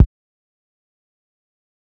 SizzKick1.wav